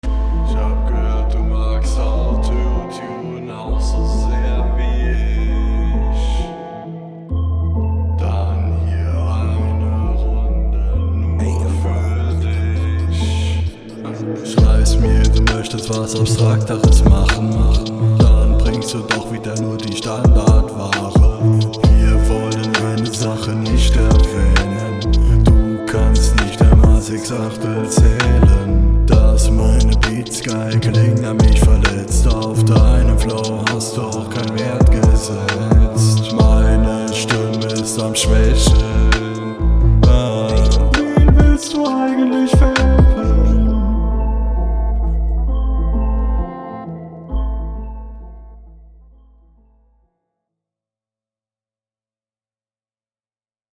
Mische eher nicht so geil aber +1 für Kreativität!